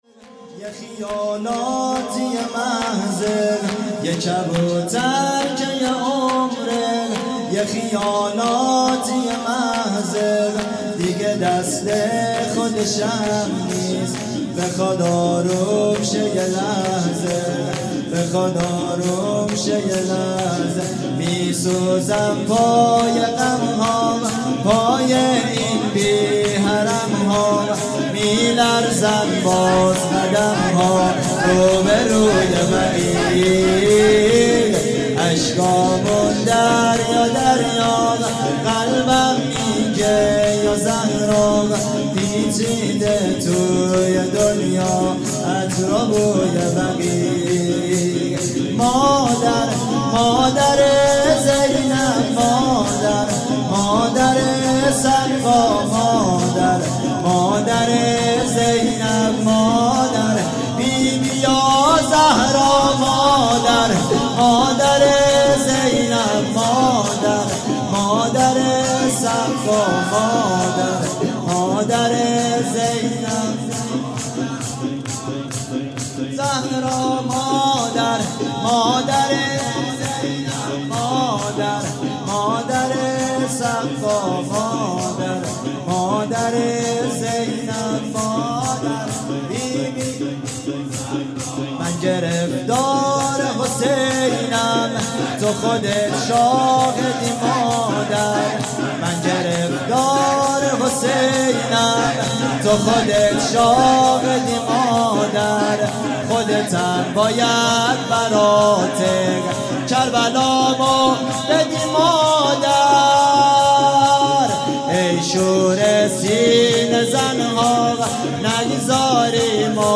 شور
یه کبوتر که یه عمره|جلسه هفتگی ۲۰ بهمن ۹۴
هیئت دانشجویی فاطمیون دانشگاه یزد